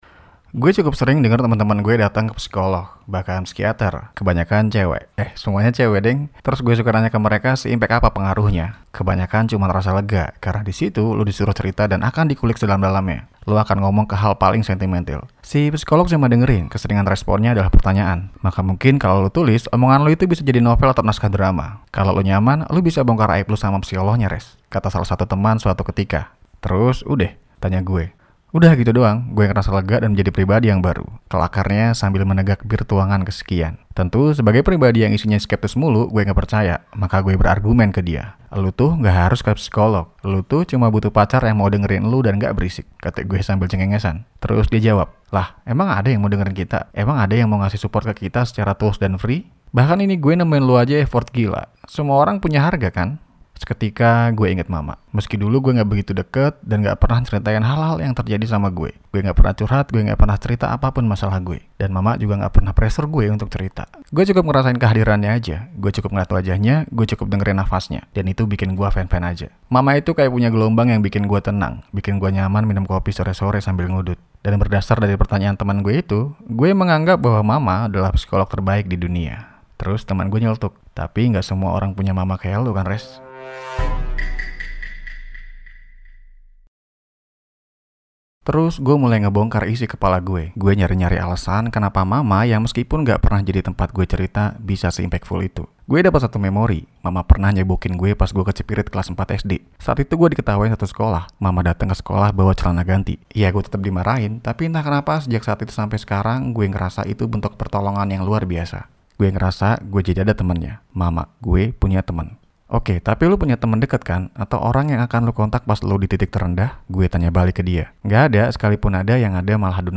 Versi Sulih Suara